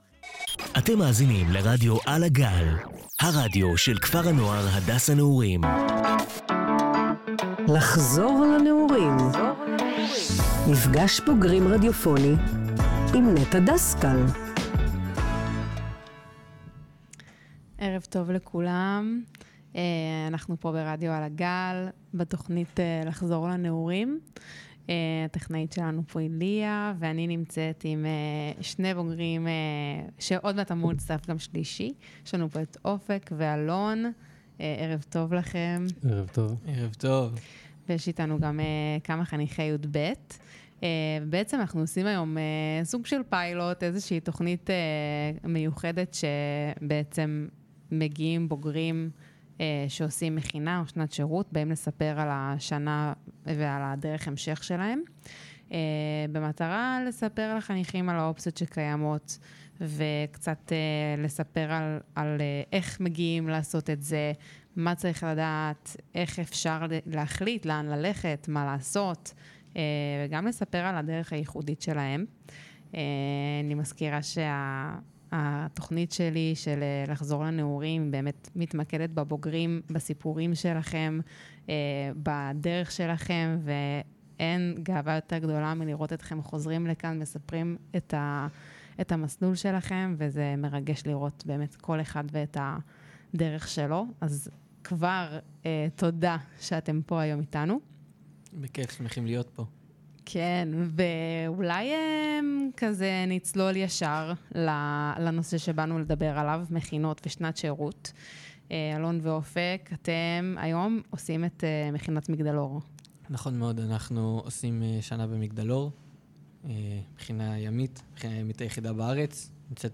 באולפן חי